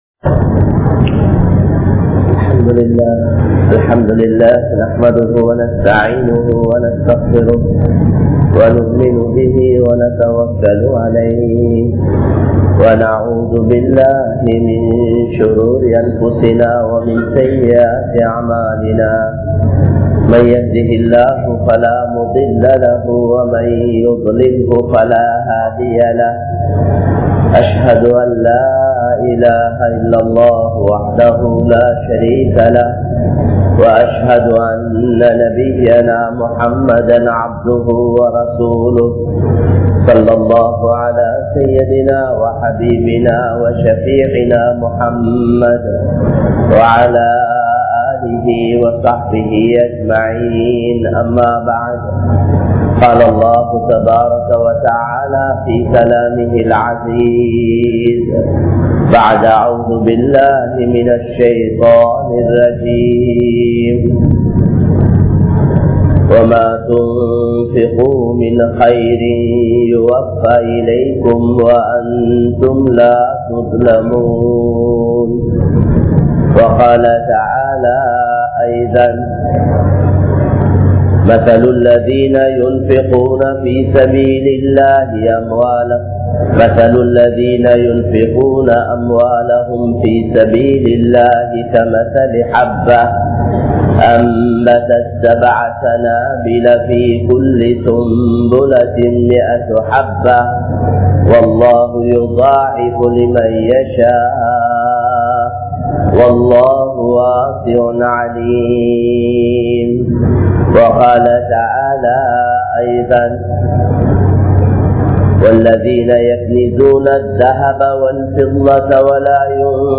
Mu`Meenin Panpuhal | Audio Bayans | All Ceylon Muslim Youth Community | Addalaichenai
Colombo 03, Kollupitty Jumua Masjith